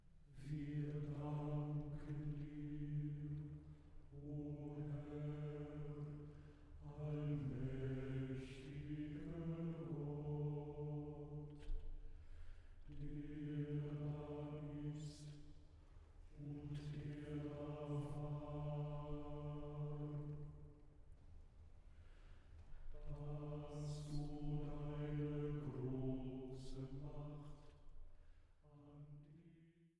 Oratorium